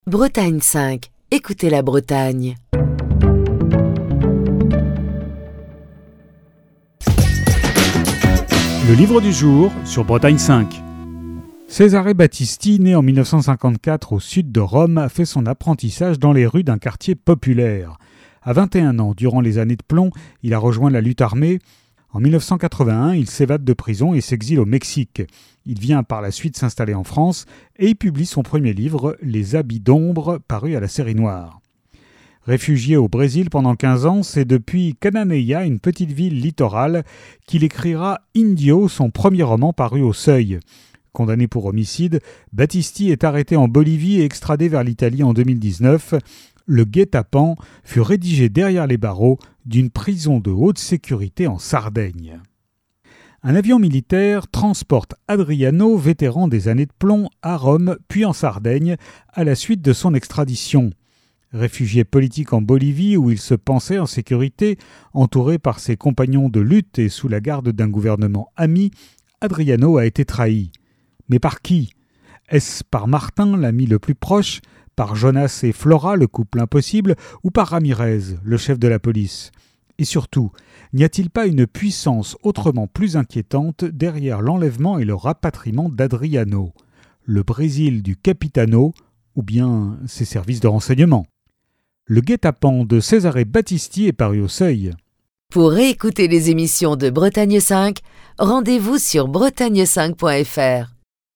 Fil d'Ariane Accueil Les podcasts Le Guet-apens - Cesare Battisti Le Guet-apens - Cesare Battisti Chronique du 30 septembre 2022.